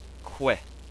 QU - as in quick